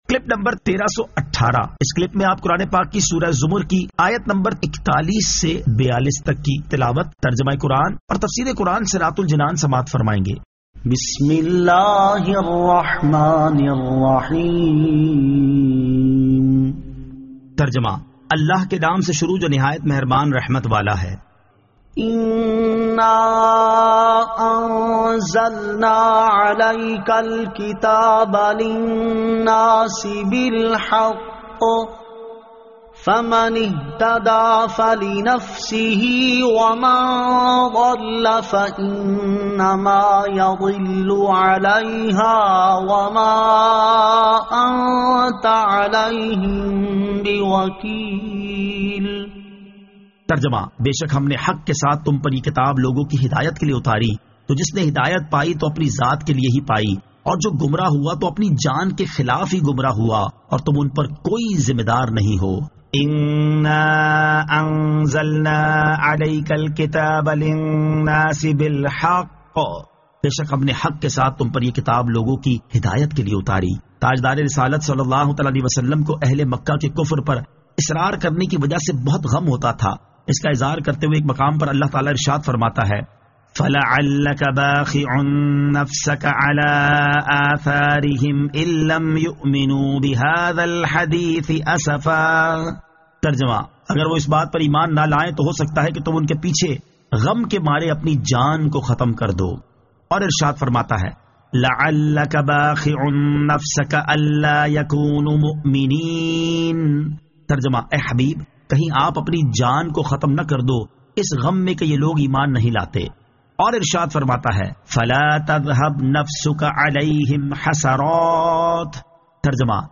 Surah Az-Zamar 41 To 42 Tilawat , Tarjama , Tafseer